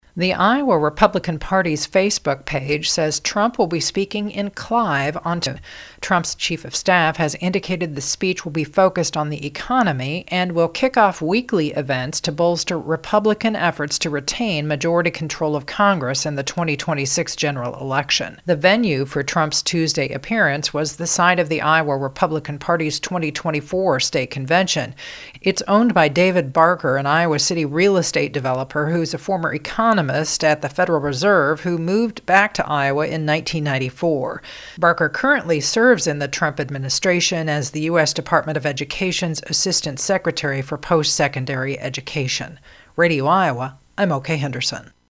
REPORTS.